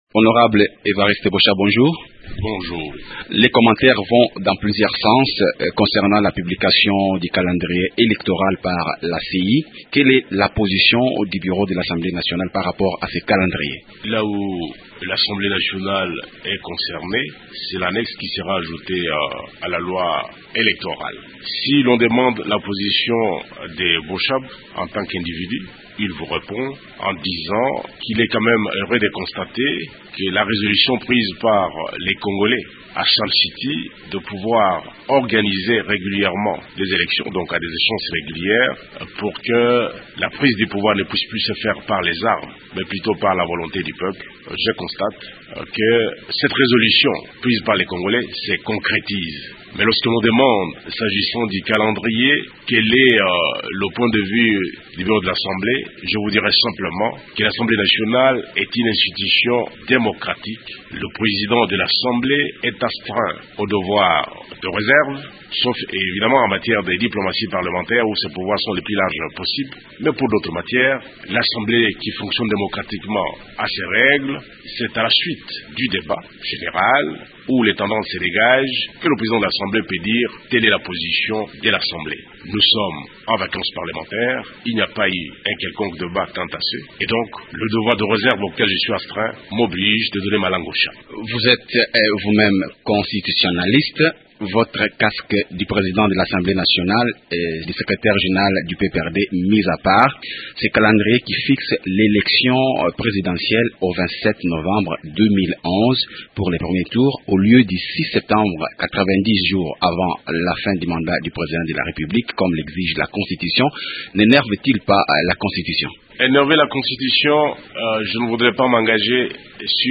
Evarite Boshab, président du parlement